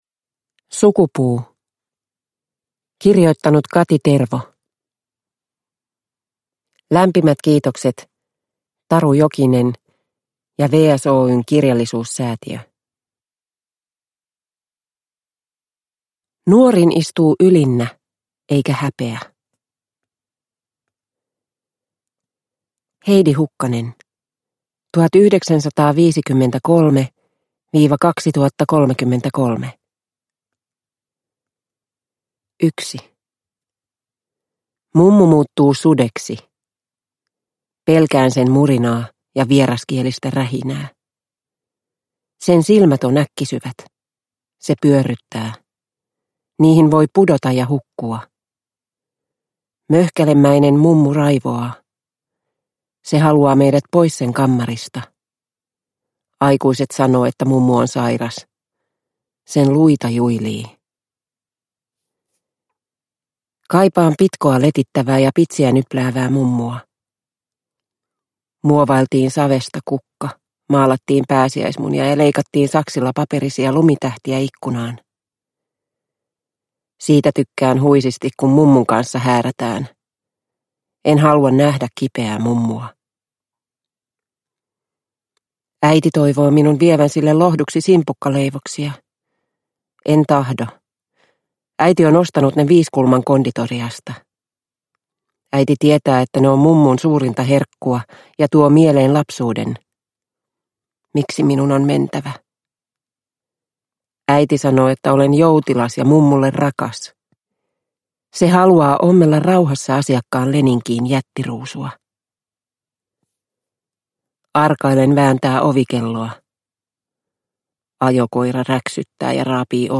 Sukupuu – Ljudbok – Laddas ner